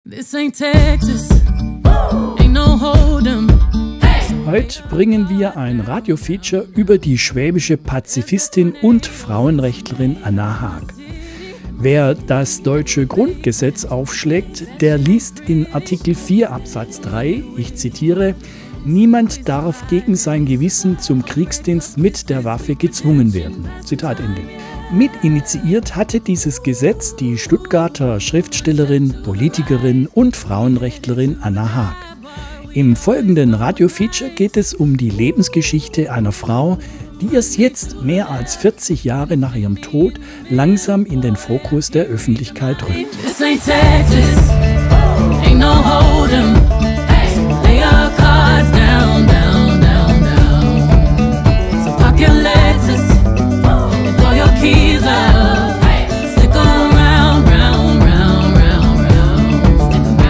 Teaser_545.mp3